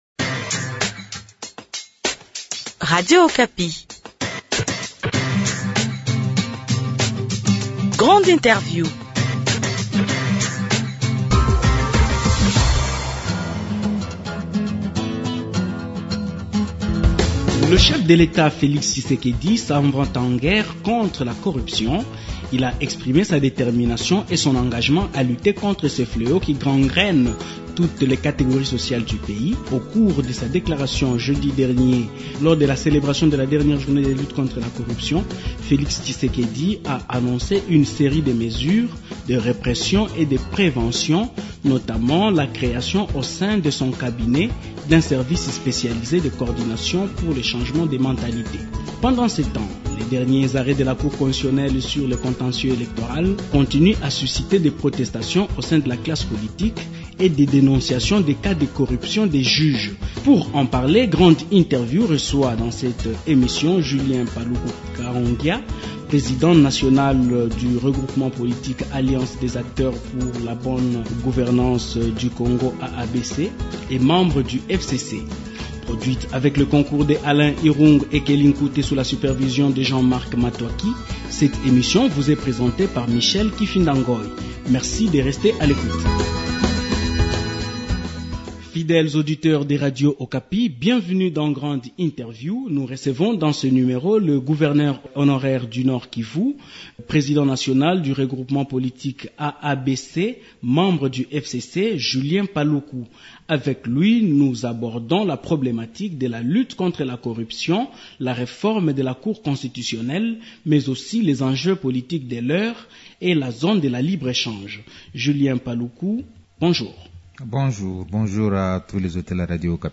Grande Interview reçoit dans ce numéro, Julien Paluku Kahongya, président national du regroupement politique Alliance des acteurs pour la bonne gouvernance du Congo (AABC) membre du FCC.